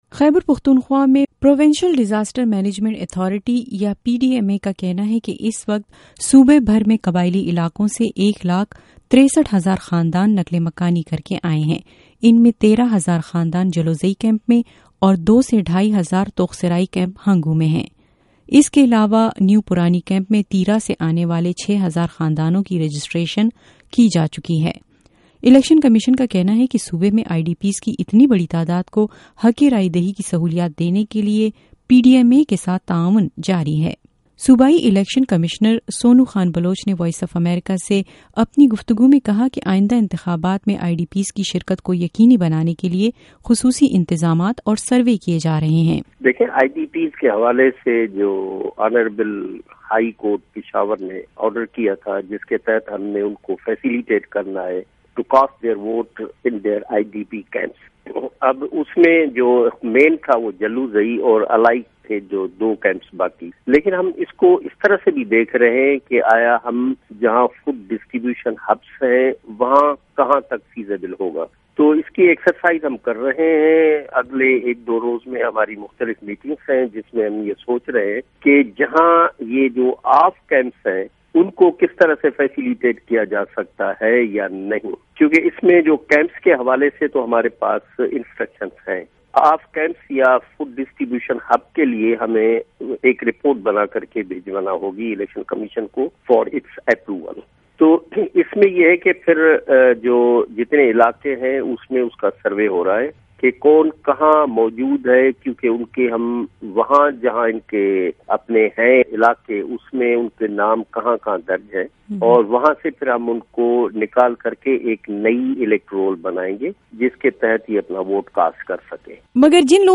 ووٹنگ اور نقل مکانی کرنے والے، خصوصی گفتگو